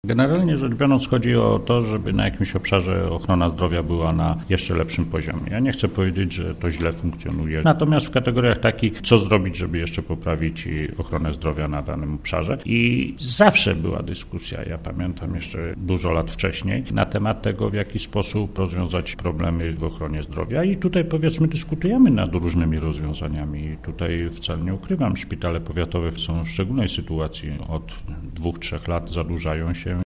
– To ważne w kontekście powstania Centrum Zdrowia Matki i Dziecka w Zielonej Górze – tłumaczy Krzysztof Romankiewicz, starosta zielonogórski.